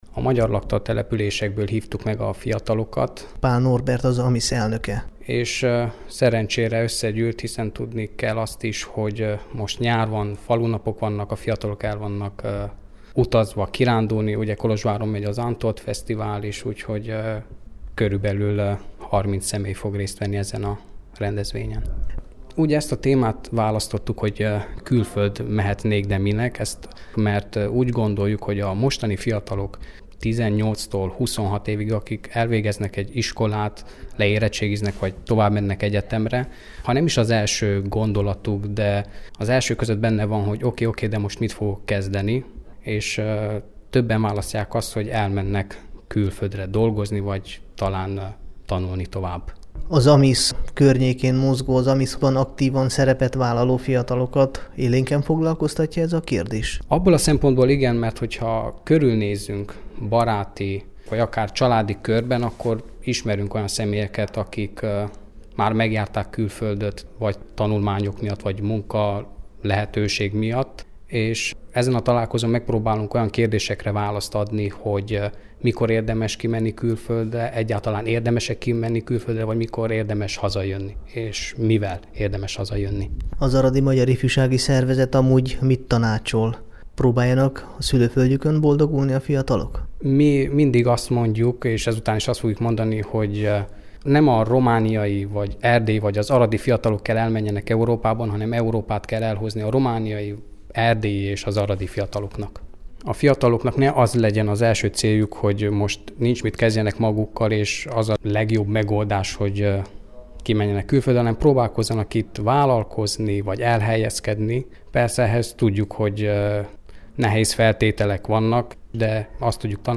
Tizenegyedik alkalommal szervezte meg az Arad Megyei Magyar Ifjúsági Találkozót az Aradi Magyar Ifjúsági Szervezet az elmúlt hétvégén.